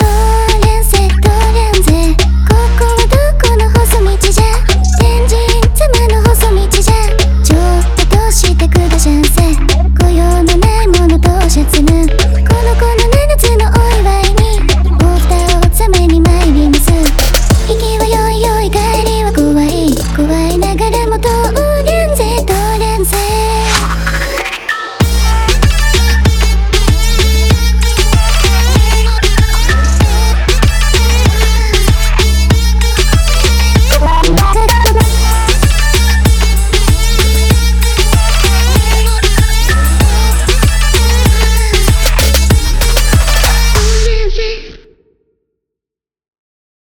とおりゃんせ レゲトンの香りを添えて
こちらドロップのリードフレーズは『とおりゃんせ』そのままですが、普通に昨今のK-Popに紛れ込んでてもおかしくなさそうなラインですよね。
2025-kpop-toryanse.mp3